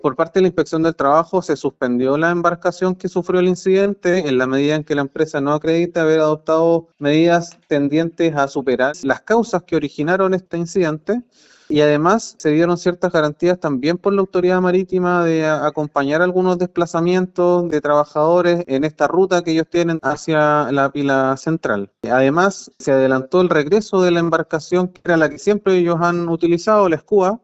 El director del Trabajo en Los Lagos, Claudio Salas, dijo que en la instancia se abordó accidente de dicha barcaza y detalló las medidas adoptadas.